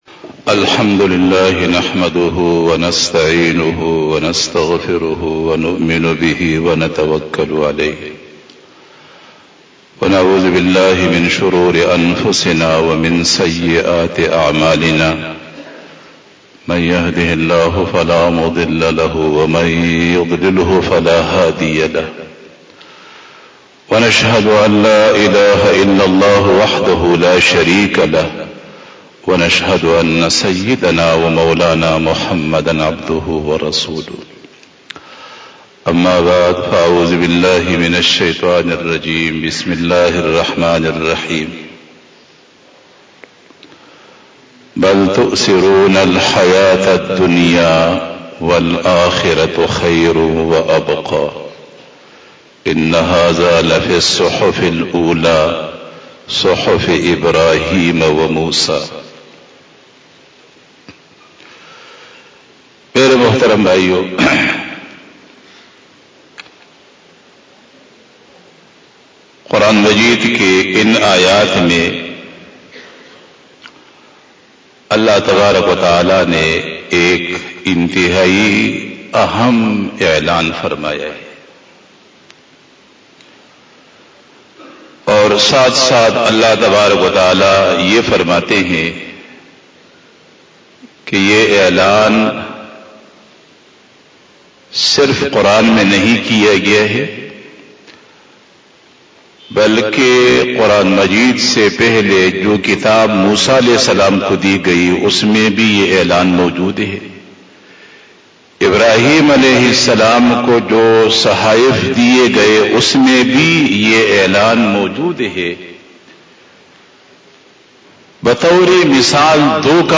07 BAYAN E JUMA TUL MUBARAK 12 February 2021 29 Jumadi us Sani 1442H)
Khitab-e-Jummah